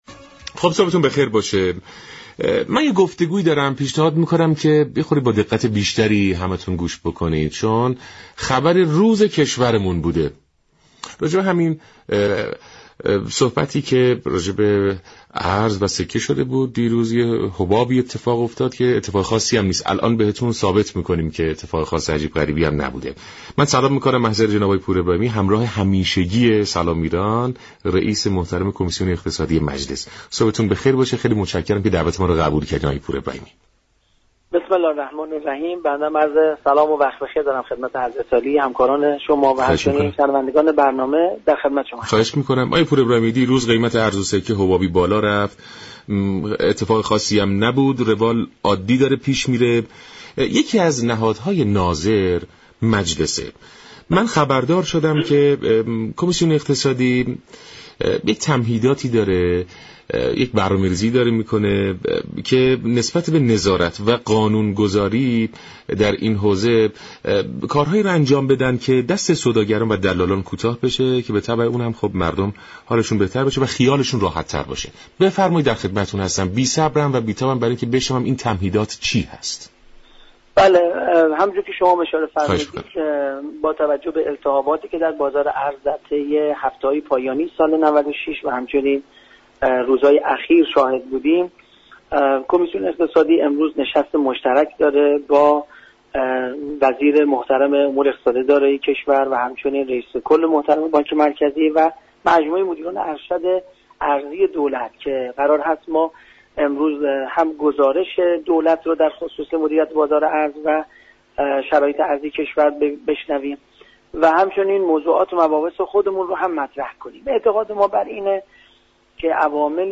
رئیس كمیسیون اقتصادی مجلس در برنامه سلام ایران رادیو ایران تاكید كرد : مجلس یك بسته ی تخصصی آماده كرده كه در جلسه با مسئولان بانكی مطرح خواهد كرد
به گزارش شبكه رادیویی ایران؛ دكتر محمدرضا پورابراهیمی رئیس كمیسیون اقتصادی مجلس در گفت و گو با رادیو ایران خاطرنشان كرد : تراز درآمد ارزی كشور مثبت است و در آمد های ارزی كشور هم بیشتر شده ؛ بنابراین التهاب بازار ارز كاملا روانی است.